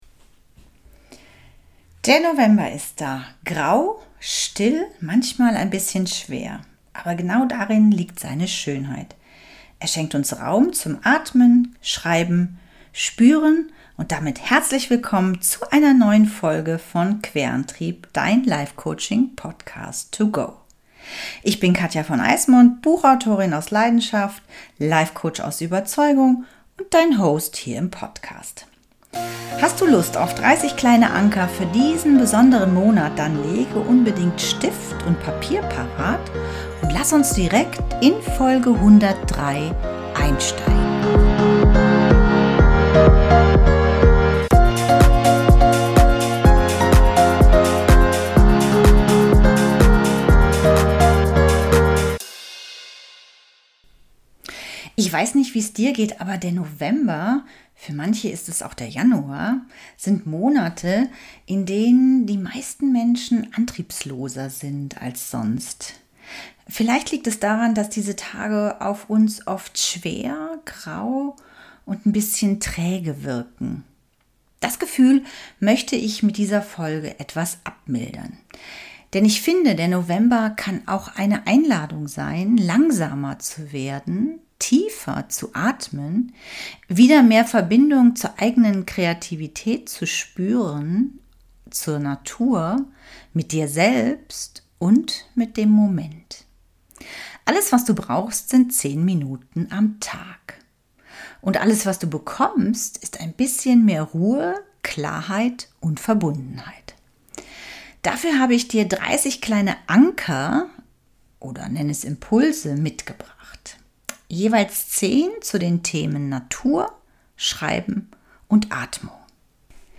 Grau, neblig, still – der November lädt ein, langsamer zu werden. In dieser Solofolge teile ich 30 kurze Achtsamkeitsimpulse, die du ganz leicht in deinen Alltag integrieren kannst.